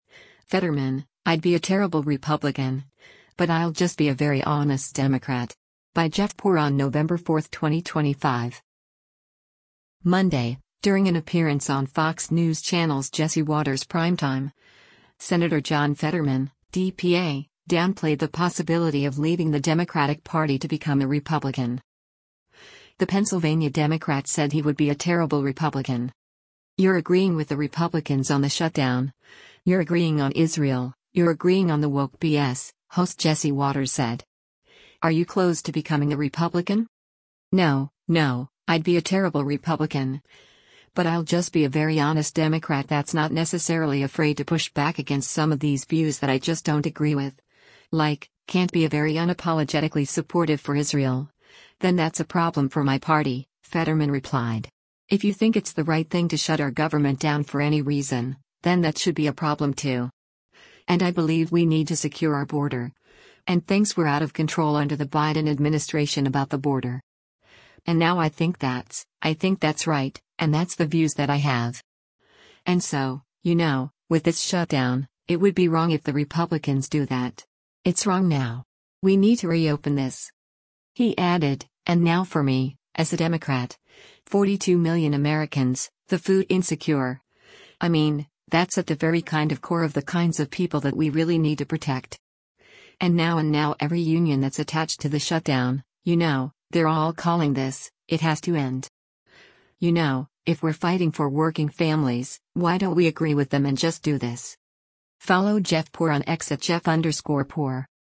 Monday, during an appearance on Fox News Channel’s “Jesse Watters Primetime,” Sen. John Fetterman (D-PA) downplayed the possibility of leaving the Democratic Party to become a Republican.